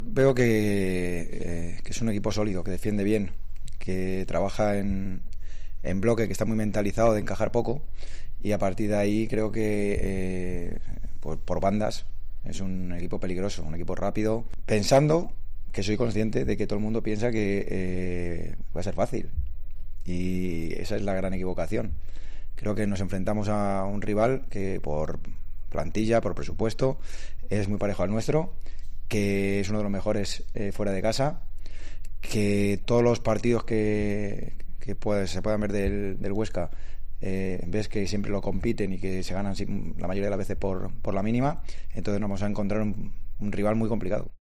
en la rueda de prensa previa al partido